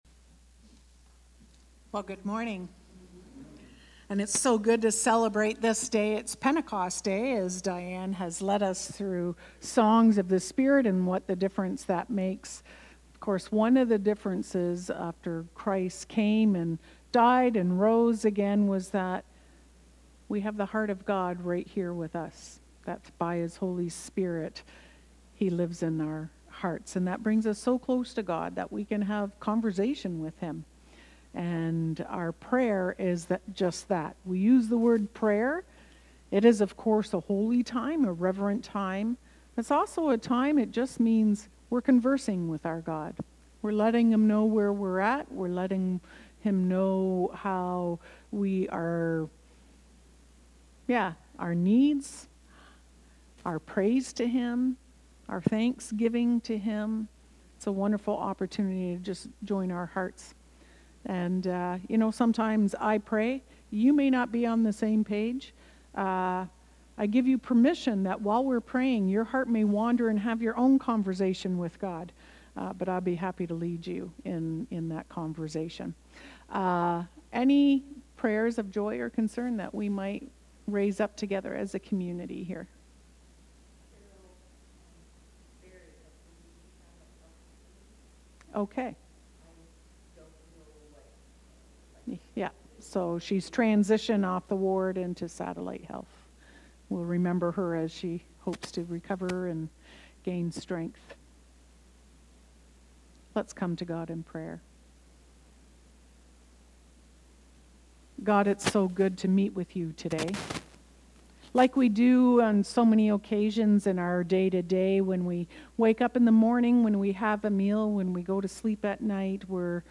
Knox Binbrook worship service June 8, 2025